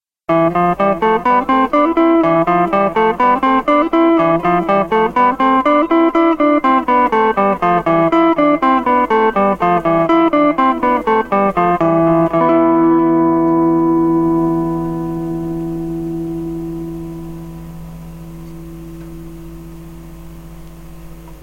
Voici des petits exemples pour se rendre compte de la sonorité (couleur) que chaque mode possède :
Mode Phrygien (degré III)
Mim7 Mode Locrien (degré VII) Sim7b5
Les exemples sont joués sur une guitare Telecaster, micro manche, avec pratiquement aucun effet (un peu de delay).
Mim7.mp3